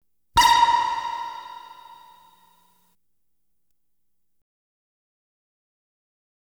Light Beam Hit Sound Effect
Download a high-quality light beam hit sound effect.
light-beam-hit-9.wav